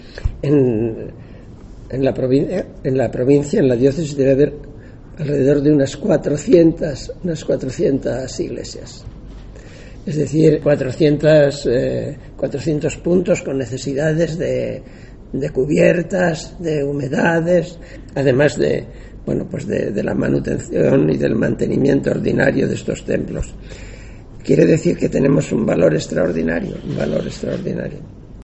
Obispo. Convenio mejora de templos